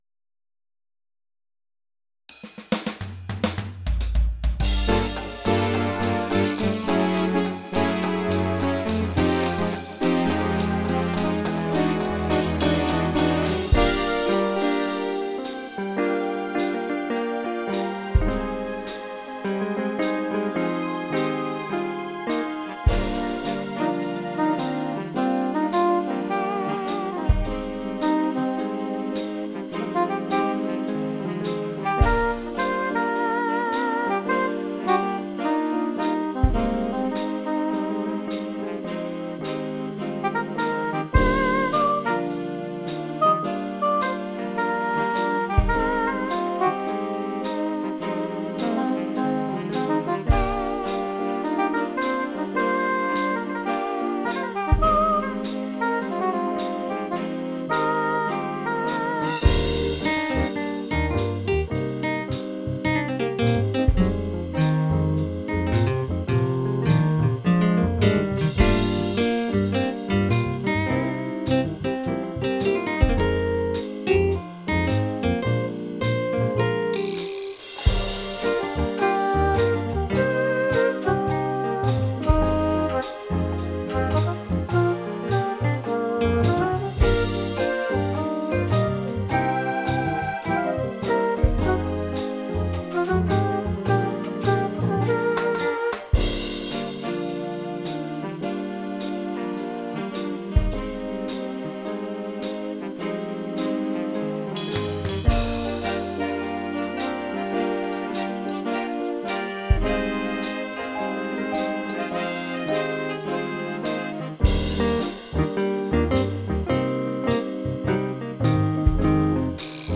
on a Roland FA-06 Music Workstation
Creation and Production done entirely on the workstation.